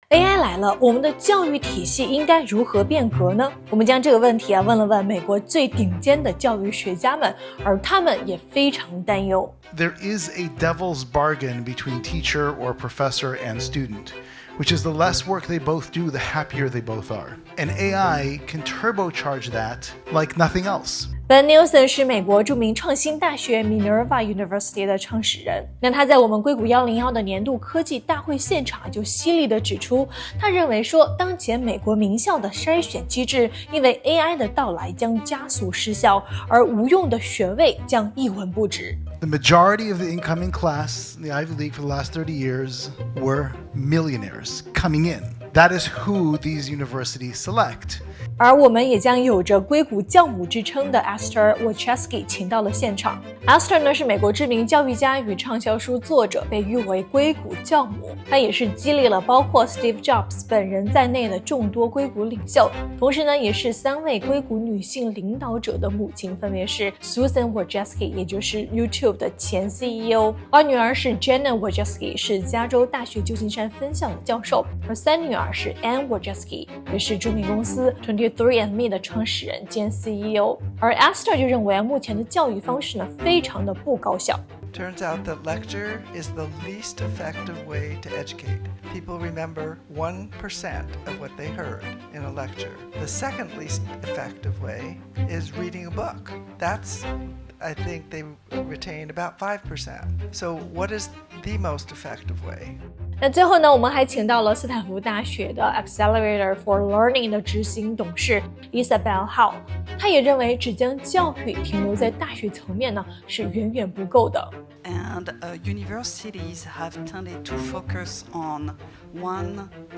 [硅谷101] 硅谷教育颠覆者发出警告：AI将淘汰不愿改革的学校｜硅谷101年度线下大会（全英） - 整点薯条吧